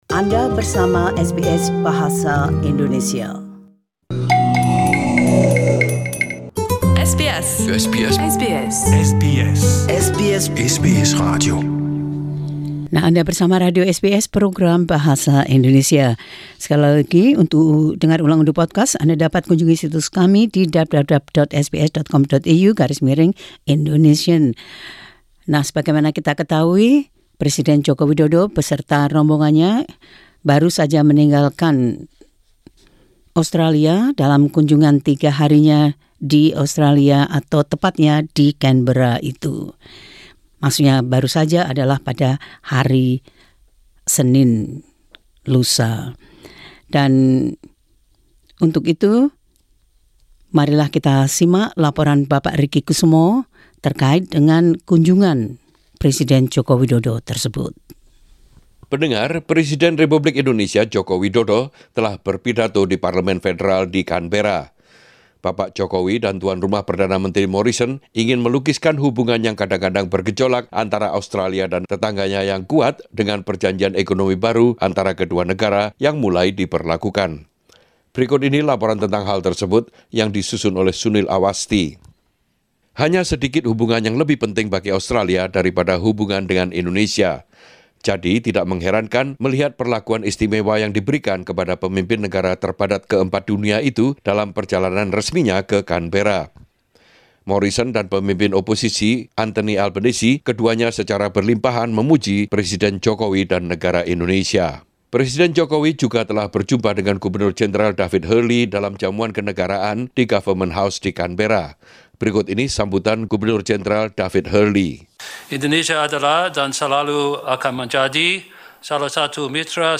Presiden Indonesia Joko Widodo Berpidato di Parlemen Australia di Canberra.
Indonesia's President Joko Widodo addresses the Australian Parliament in Canberra (AAP).